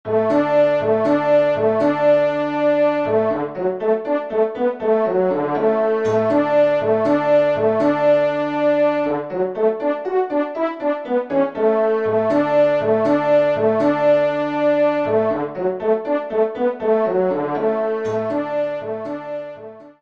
Trompe en exergue